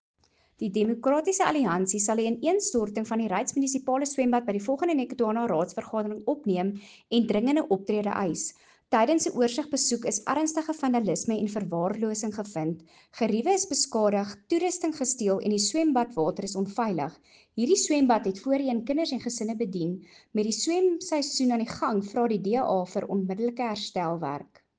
Afrikaans soundbite by Cllr Anelia Smit.